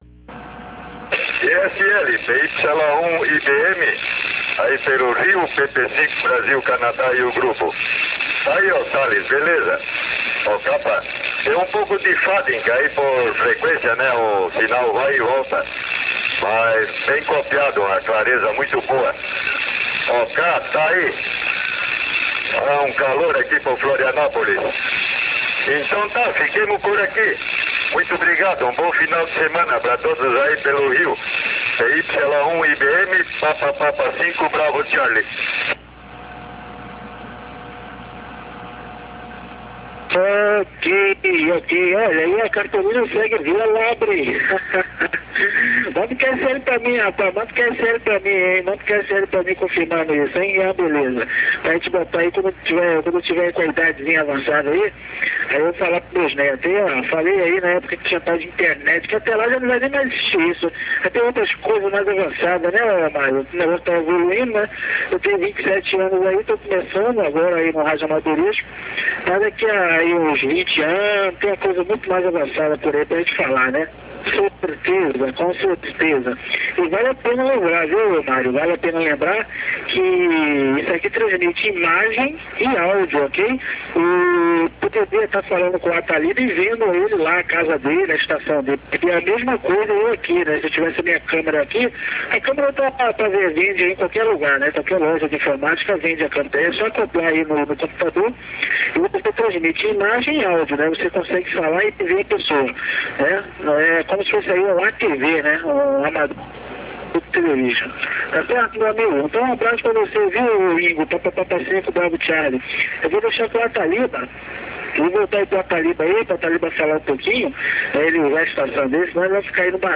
Abaixo você tem os arquivos no formato "realmedia" da gravação de todo aquele contato.
Rodada Virtual Via Internet :